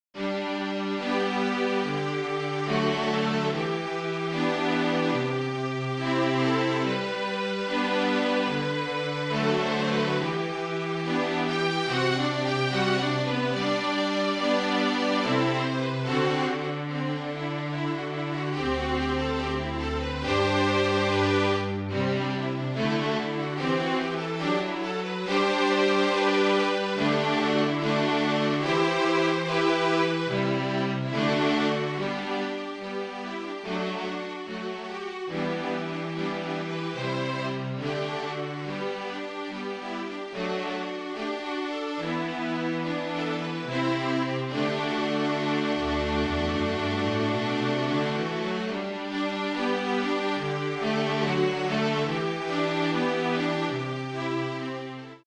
FLUTE TRIO
Flute, Violin and Cello (or Two Violins and Cello)